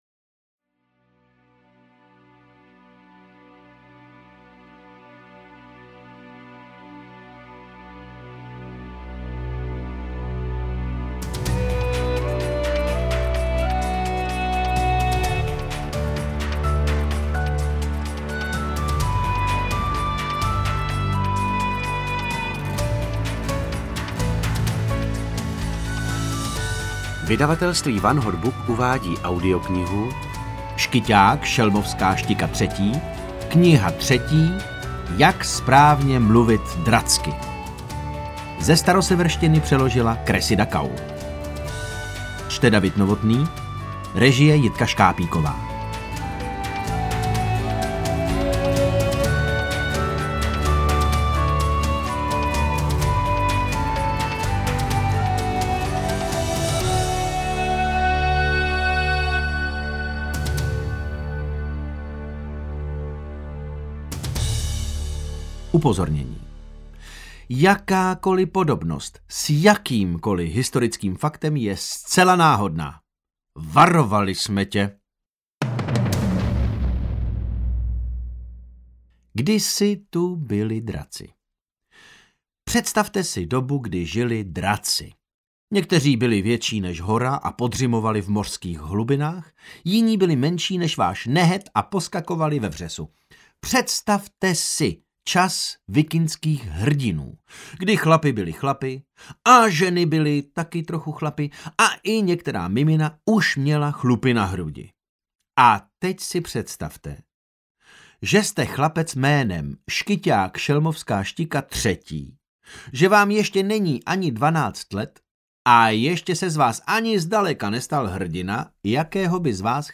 Interpret:  David Novotný
AudioKniha ke stažení, 19 x mp3, délka 3 hod. 56 min., velikost 579,0 MB, česky